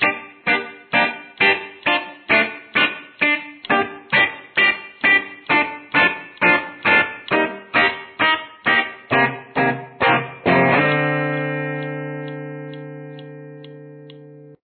Each chord is performed with a staccato.